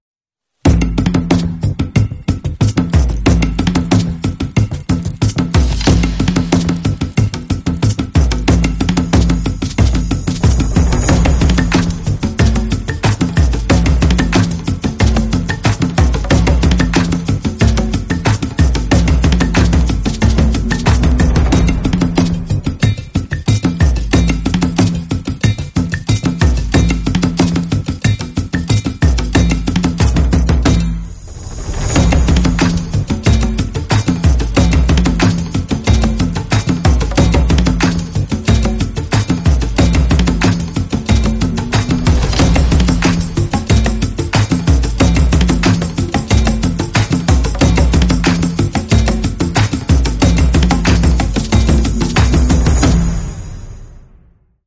Percussion World